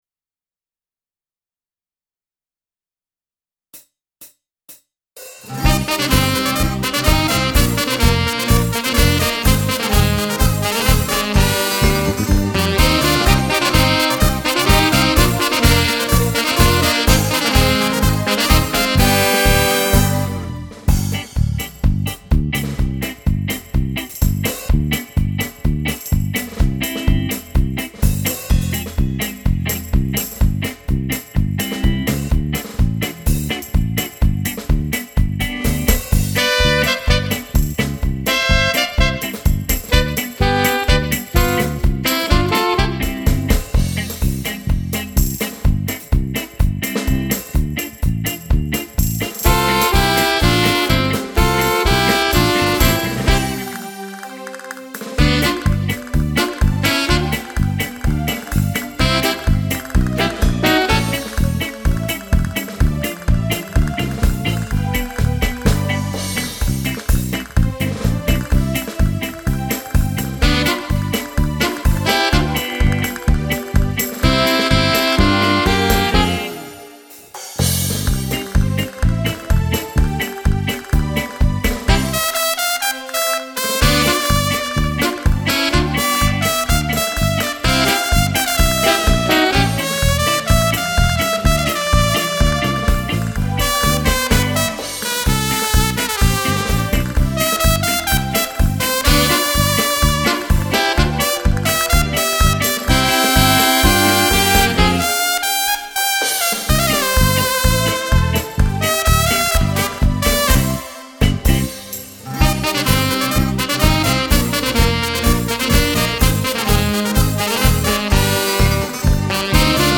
Paso doble
Fisarmonica